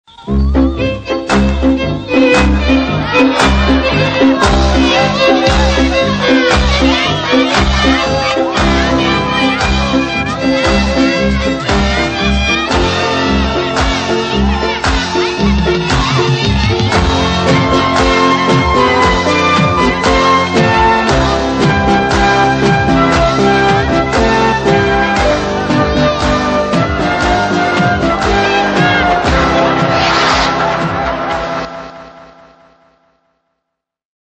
Zaverečný svadobný tanec